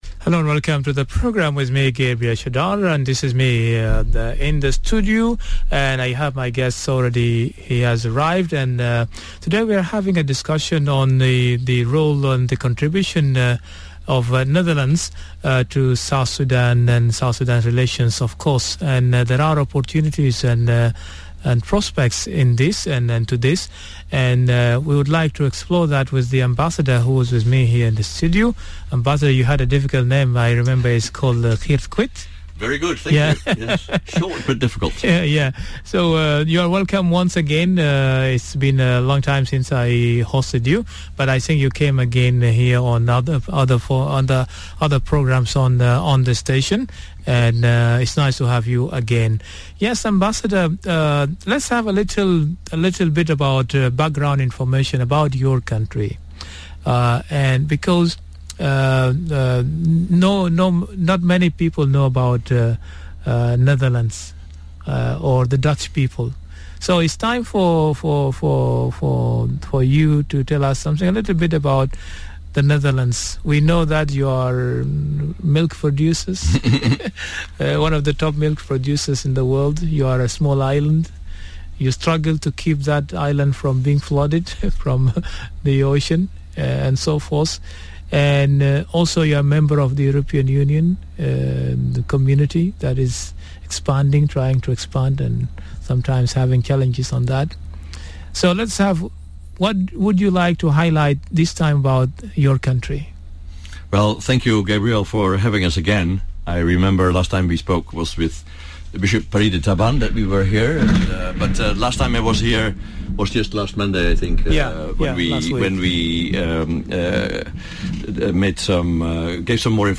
Speaking on the Nationwide Program, Ambassador Geert Guet said building trust and confidence is essential to attract more international support.